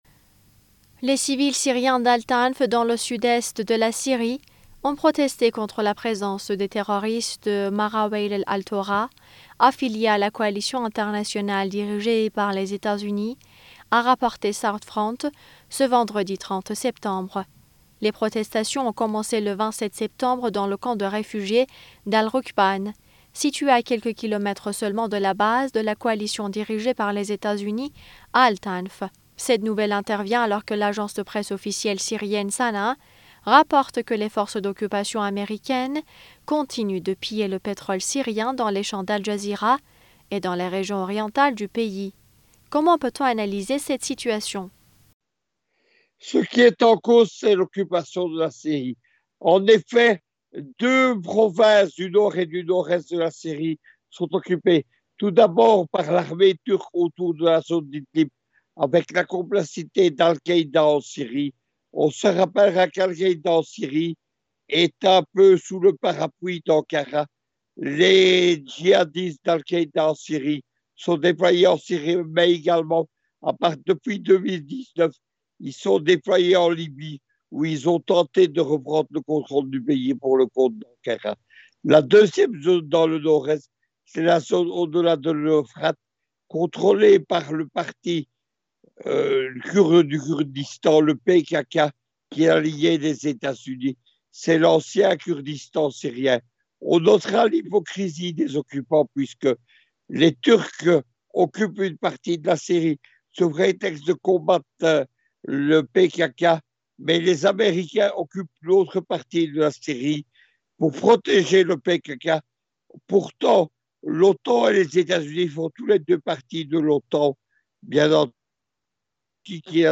Mots clés Syrie Israël usa interview Eléments connexes Teva, le labo israélien, devient l’un des plus détestés au monde… mais pourquoi ?